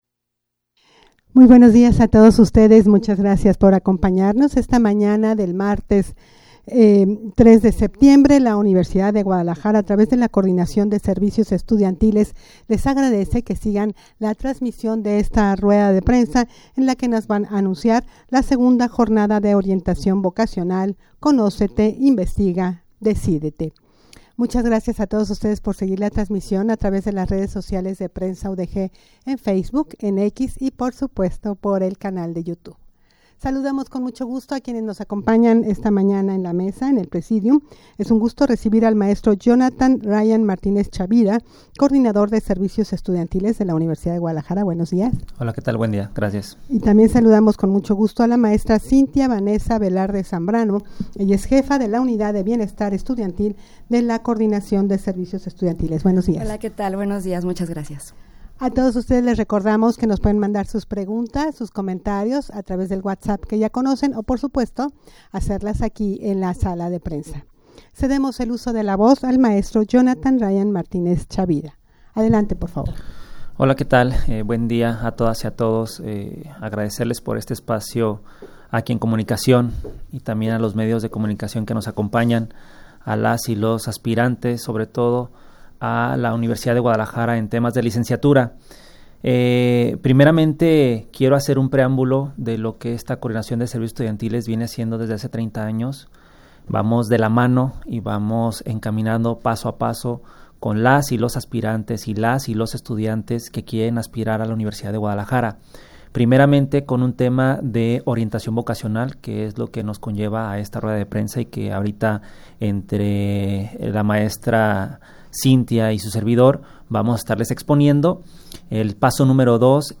rueda-de-prensa-para-anunciar-ii-jornada-de-orientacion-vocacional-virtual-conocete-investiga-decidete.mp3